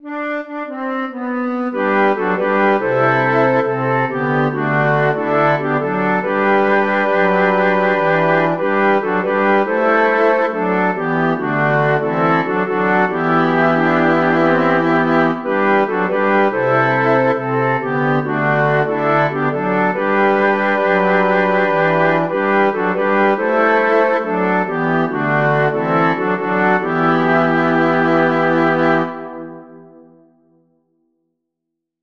Chants de Prière universelle Téléchargé par